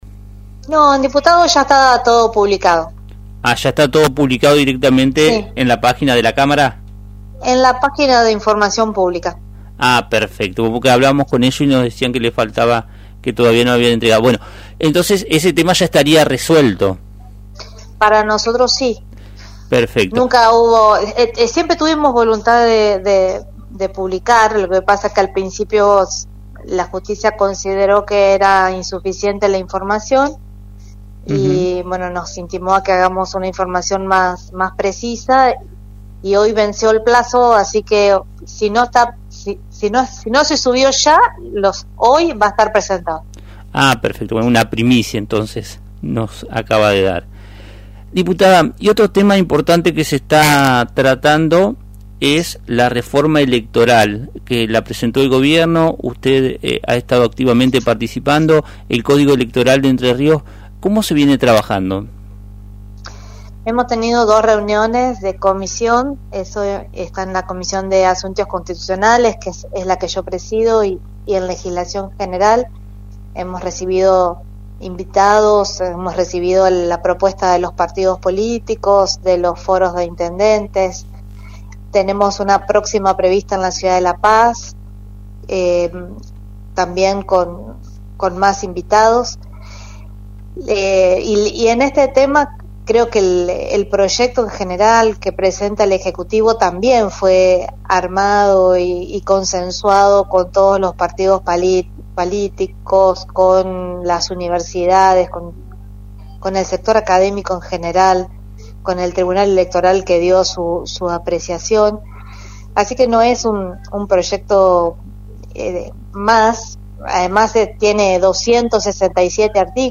La diputada provincial Gabriela Lena detalló en Radio Victoria los avances en la reforma del Código Electoral de Entre Ríos, que propone un cambio al sistema de boleta única de papel, entre otras modificaciones importantes para mejorar la transparencia y la equidad en el proceso electoral.
Gabriela Lena – Dip. Provincial